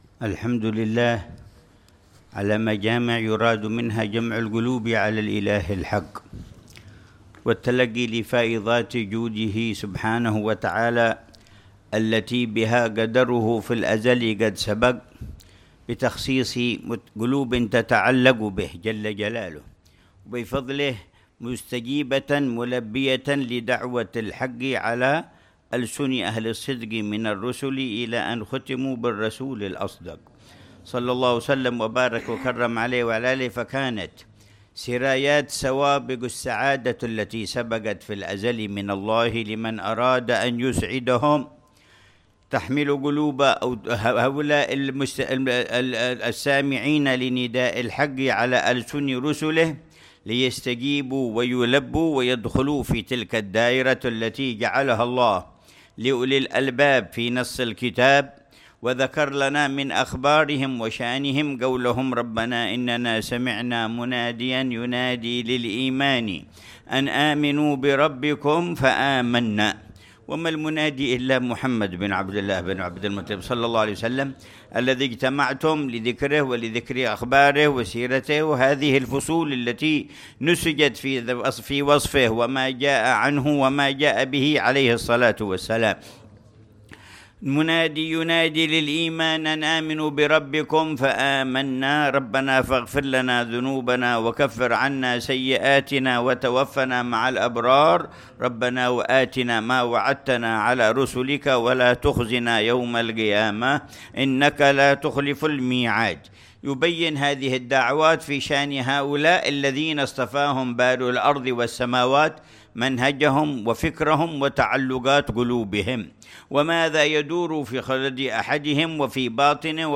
كلمة العلامة الحبيب عمر بن محمد بن حفيظ، في مجلس البردة، في مسجد السقاف، مدينة صلالة، سلطنة عمان، ليلة الإثنين 9 ربيع الأول 1447هـ